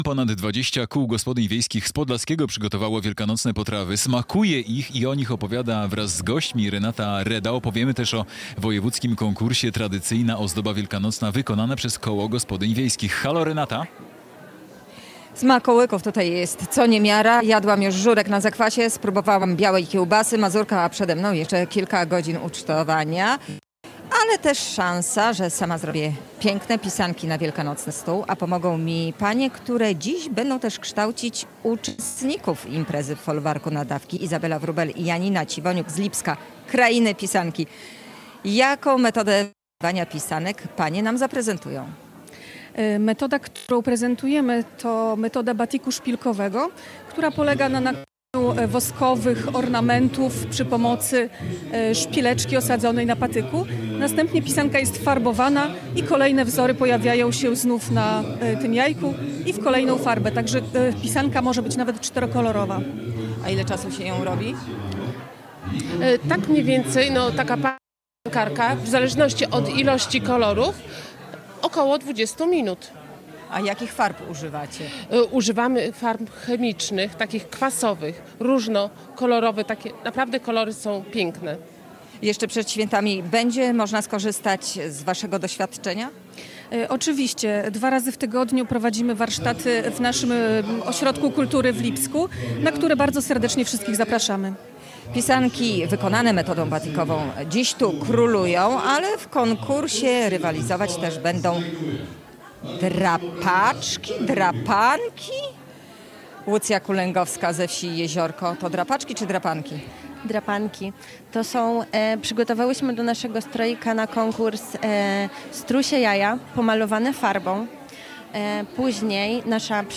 Z gospodyniami o tradycyjnych ozdobach wielkanocnych rozmawia